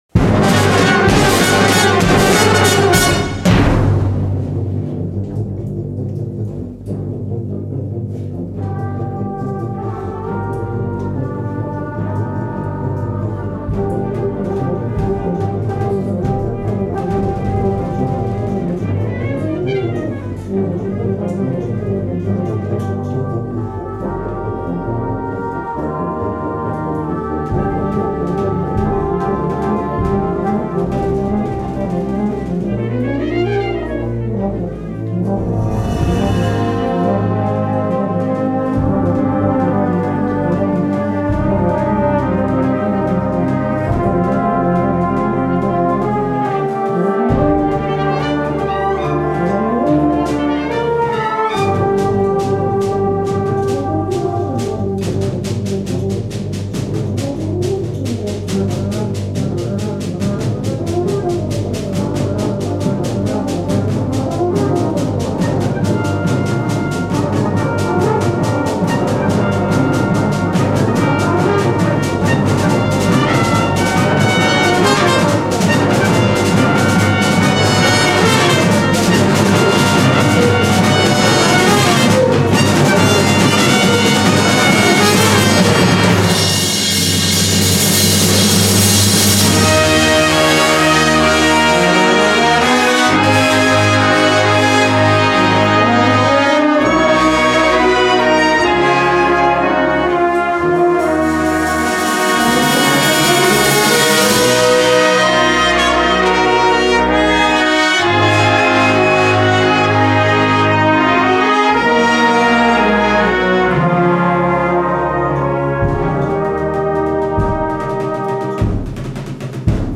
Site-Specific Composition for Brass and Voice - Falmouth University Research Repository (FURR)